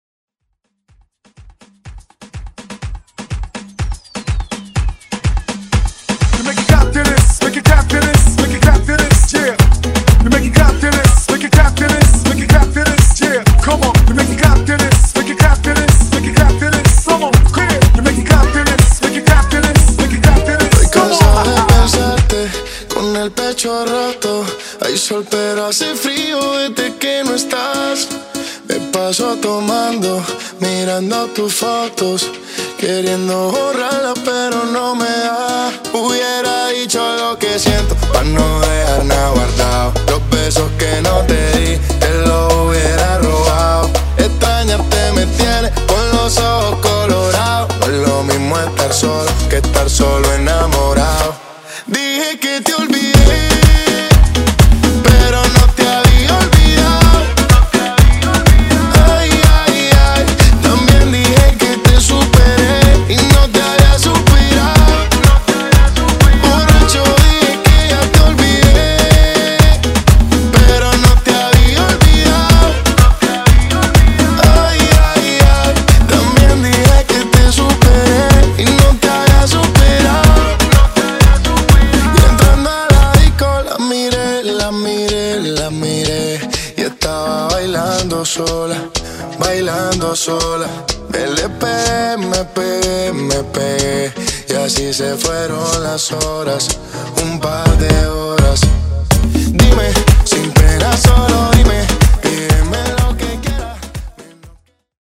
Genre: LATIN
Clean BPM: 125 Time